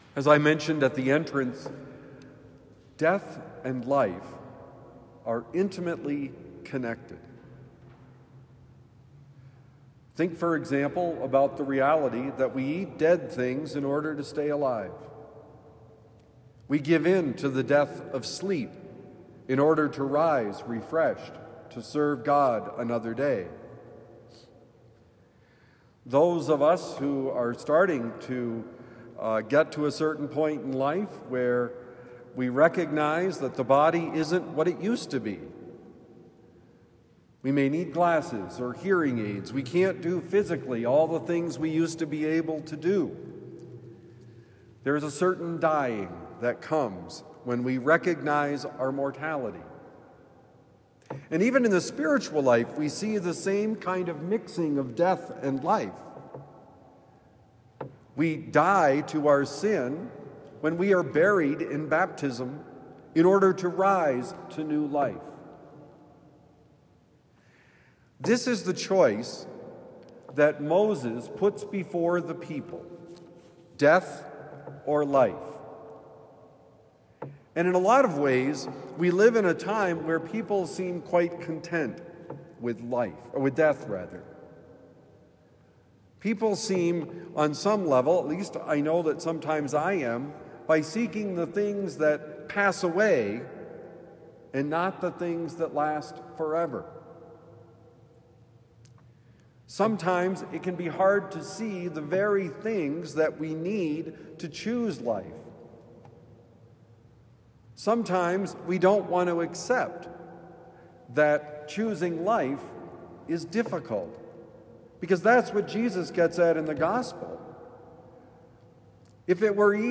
Life and Death: Homily for Thursday, February 19, 2026